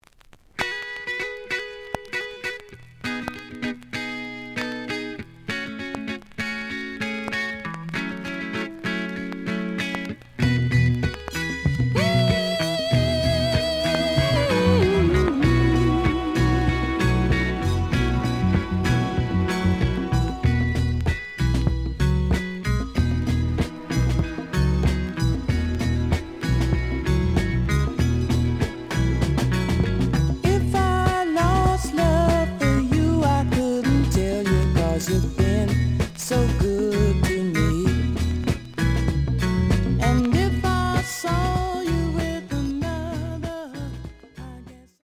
The audio sample is recorded from the actual item.
●Genre: Soul, 70's Soul
Some click noise on beginning of B side, but almost good.)